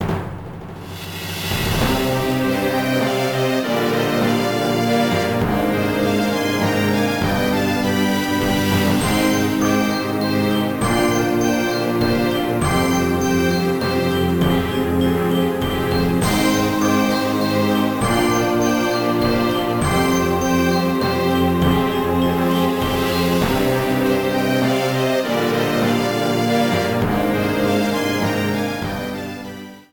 Clipped to 30 seconds and added fade-out.